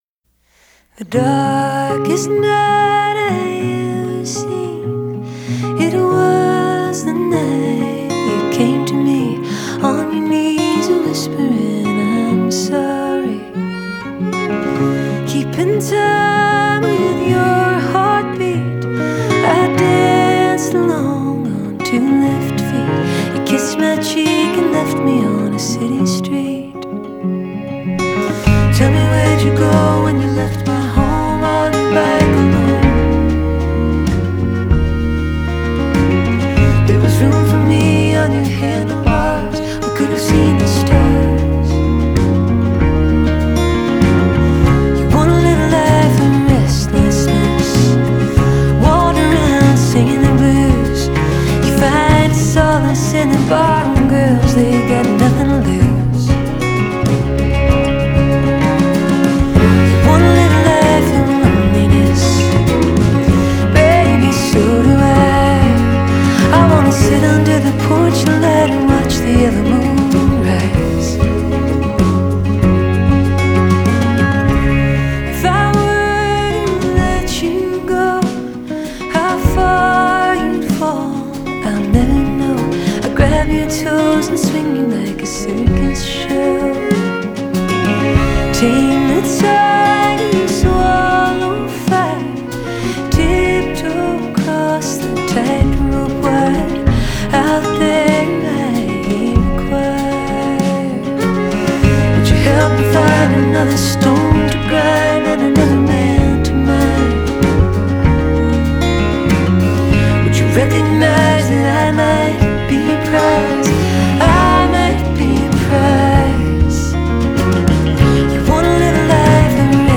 bravely erratic piece of acoustic songcraft